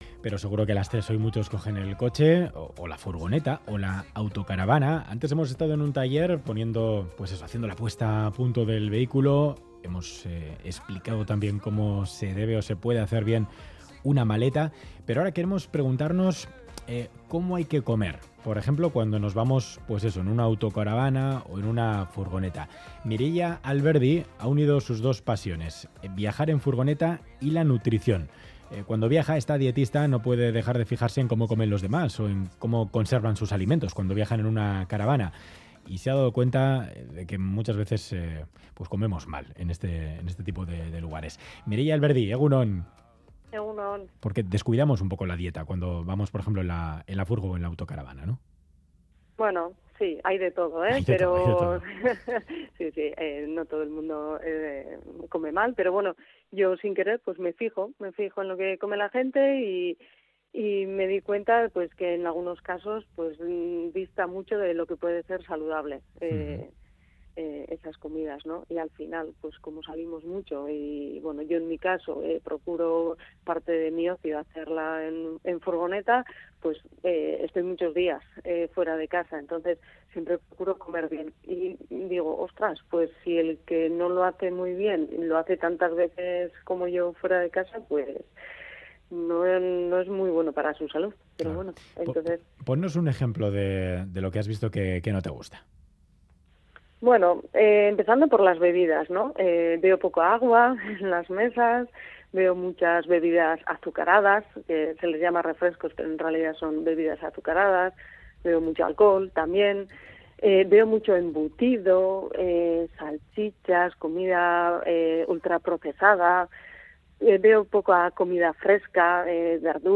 Además, hemos estado en el camping de Sopela.